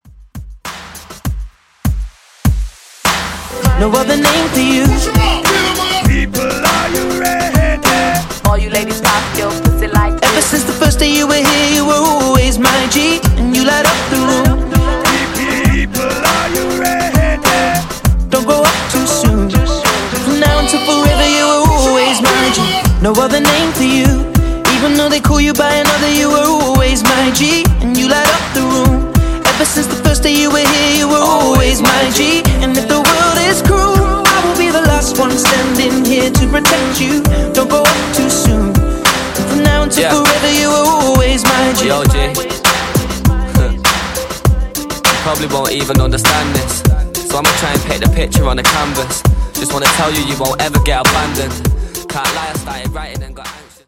Genre: RE-DRUM Version: Dirty BPM: 100 Time